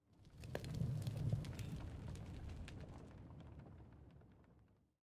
Minecraft Version Minecraft Version latest Latest Release | Latest Snapshot latest / assets / minecraft / sounds / block / smoker / smoker1.ogg Compare With Compare With Latest Release | Latest Snapshot
smoker1.ogg